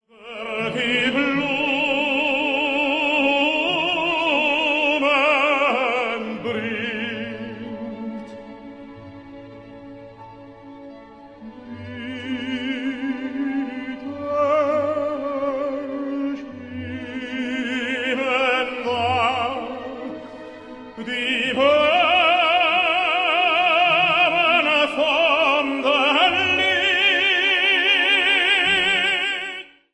- Harp - Celesta - Children Choir - Strings length
key: F-major